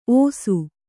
♪ ōsu